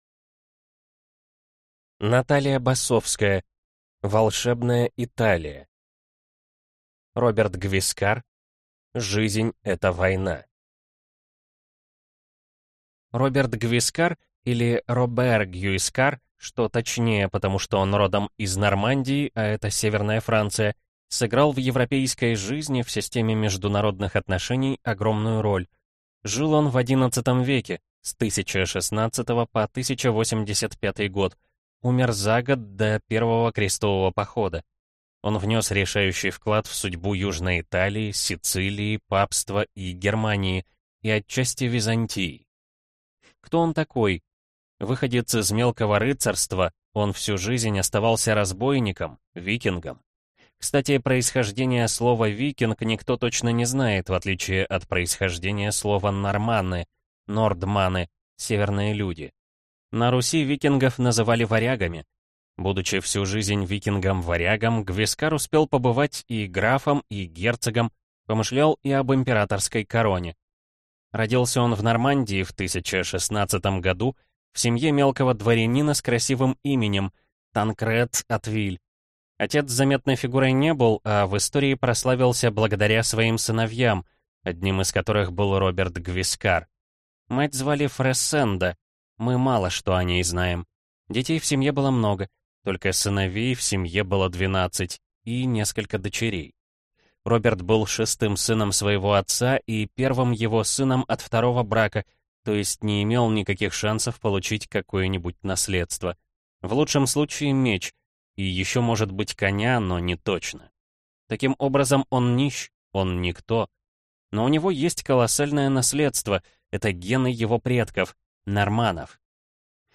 Аудиокнига Волшебная Италия | Библиотека аудиокниг